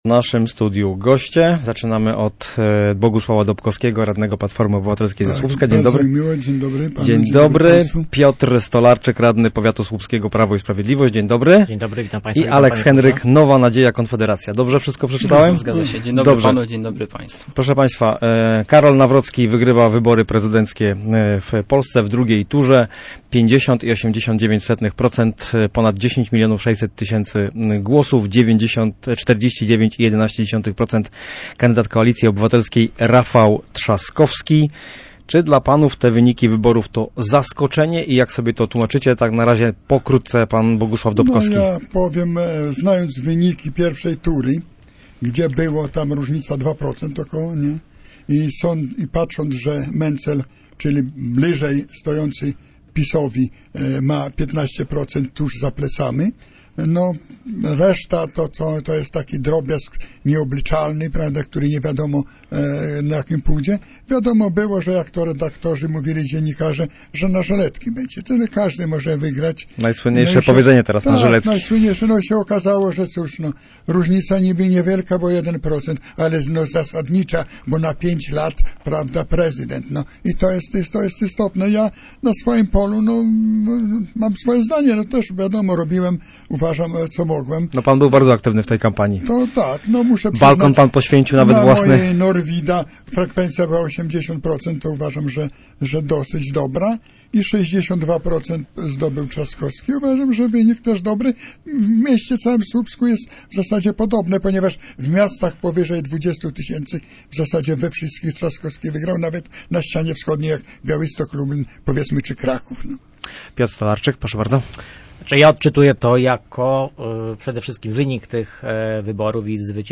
Lokalni politycy komentują